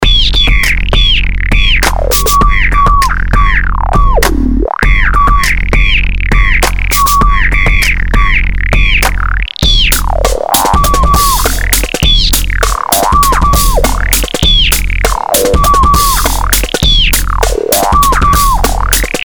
Die Charakteristka Low-, Band- und High-Pass ermöglichen auch eine Zweckentfremdung der Bassdrum für seltsame hochfrequente Geräuschkulissen, die an defekte Schaltungen erinnern.
Hier sind zwei PunchBox-Instrumente am Werk: Die erste produziert einen klassischen Bassdrum-Klopfer, die zweite (synchron) ein moduliertes Piepsen: